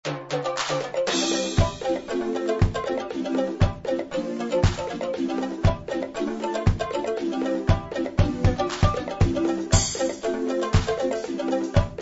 demos are 10sec .mp3 mono 32Kbit/s